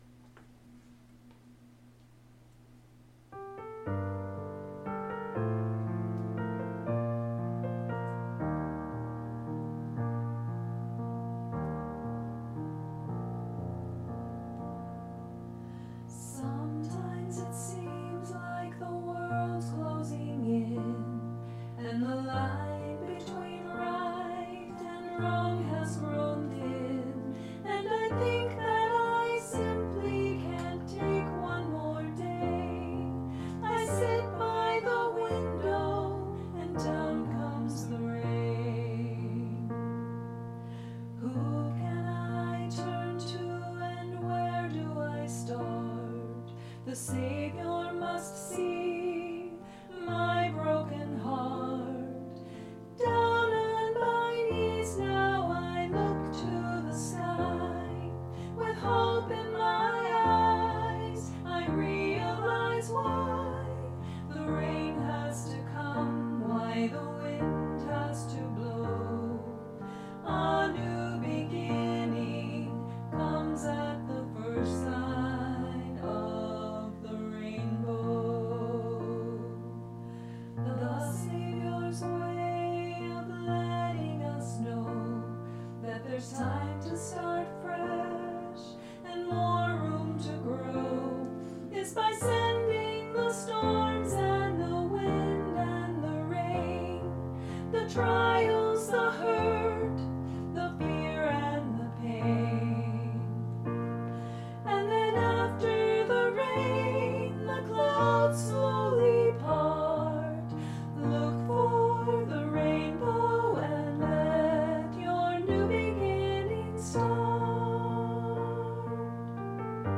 Voicing/Instrumentation: SA , Duet , Guitar Chords Available